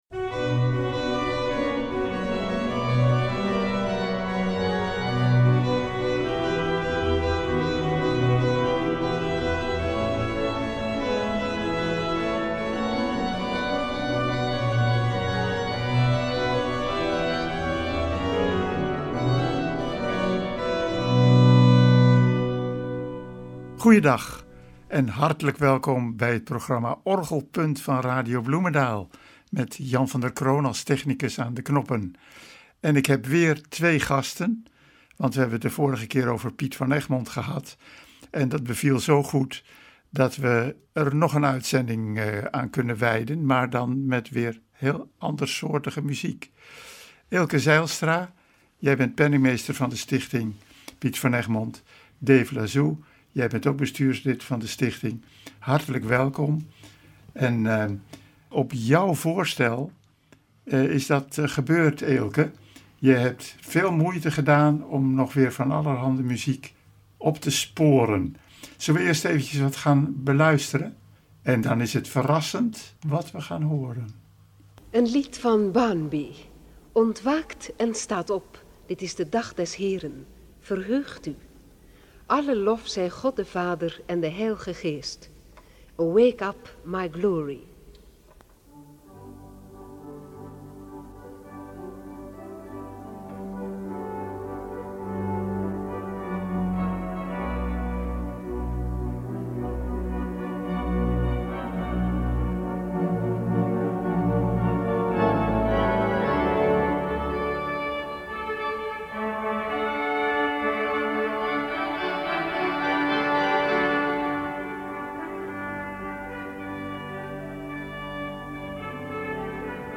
Aansprekende improvisaties over bekende liederen, afgewisseld met klassieke stukken van Bach tot Hendrik de Vries passeren de revue.